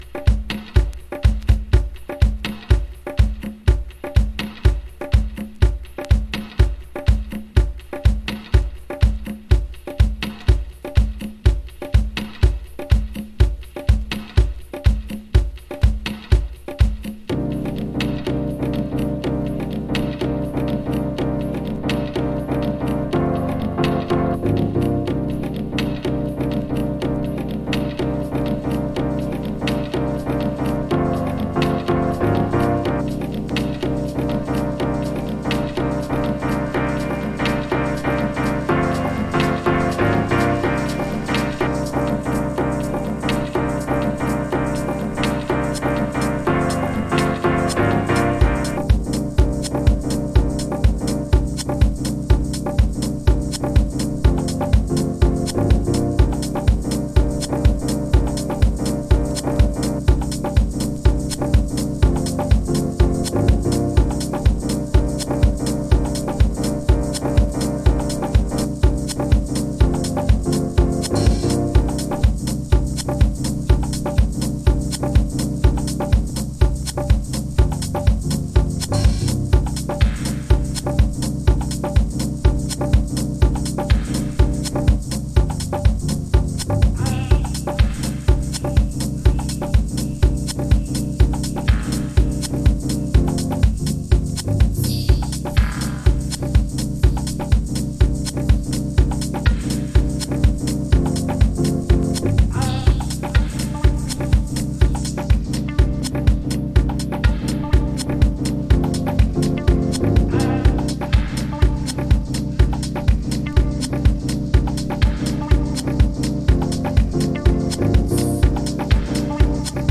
House / Techno
いつもより少しスローダウンしたブギー・グルーヴに煌びやかな音響のデイドリーミング・ハウス。
Hydraulic guitar